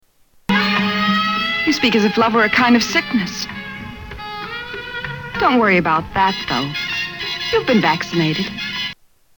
And God Created Woman 1956 Clip 1-Dubbed